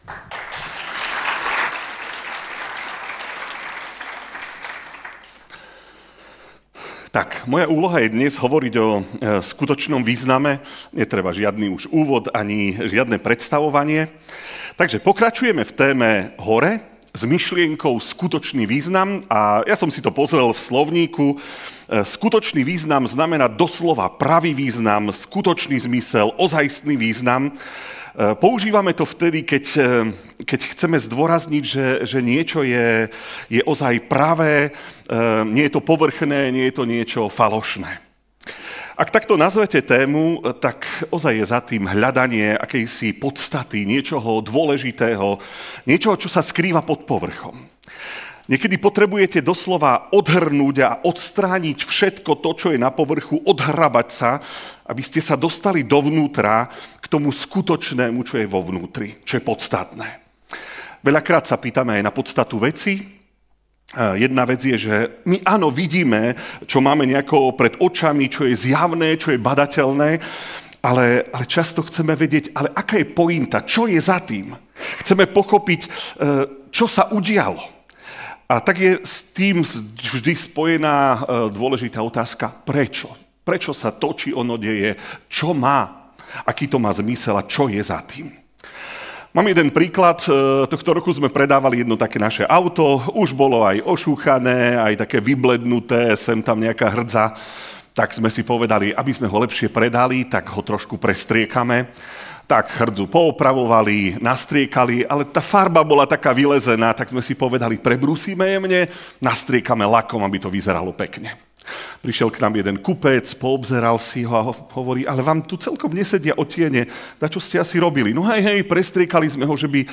Ako odznelo na konferencii EVS v Žiline.